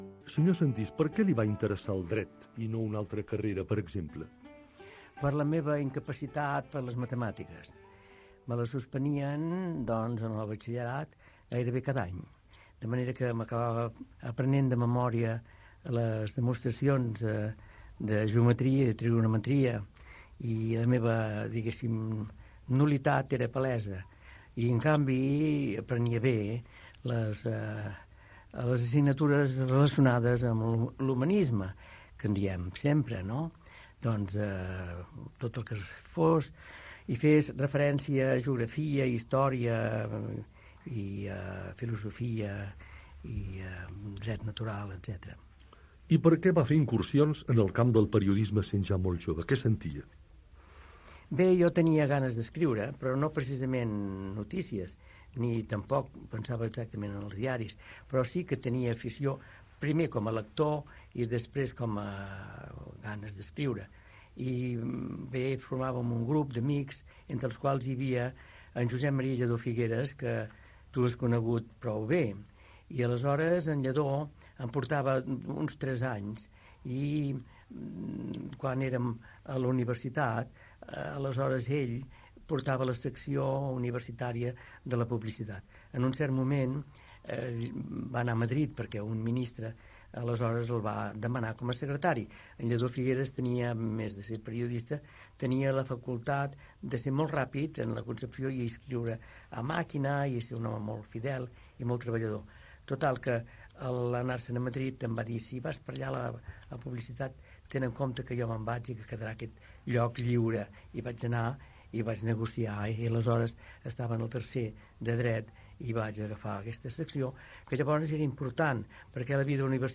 Entrevista al periodista Carles Sentís sobre la seva trajectòria professional